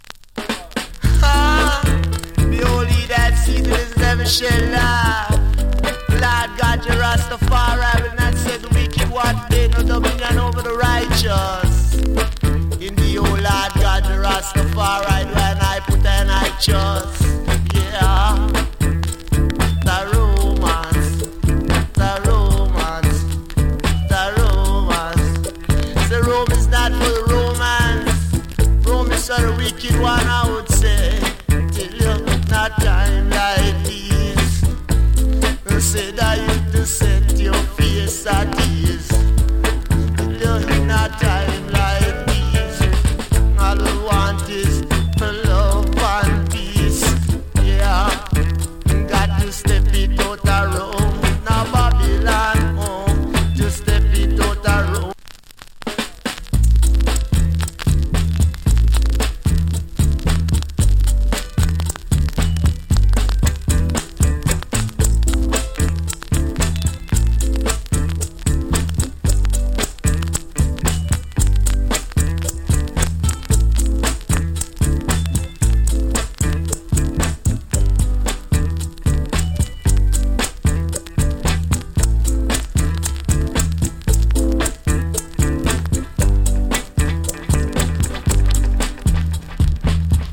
A：VG / B：VG SLD. ＊小キズ少し有り。スリキズ有り。チリ、パチノイズ多数有り。
ROOTS DEE-JAY ! ＋ FINE DUB.